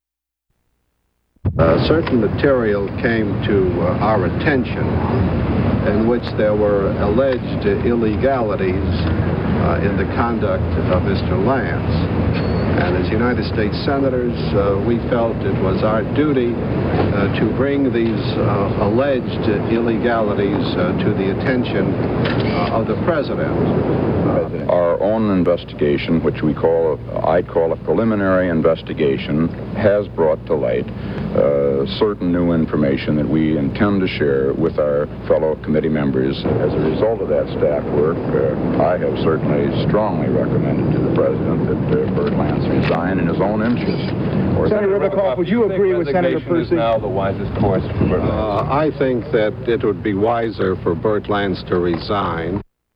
Senators Ribicoff and Percy speak to the press about their investigation of Bert Lance's financial affairs